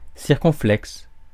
Ääntäminen
Synonyymit accent circonflexe Ääntäminen France: IPA: [siʁ.kɔ̃.flɛks] Haettu sana löytyi näillä lähdekielillä: ranska Käännöksiä ei löytynyt valitulle kohdekielelle.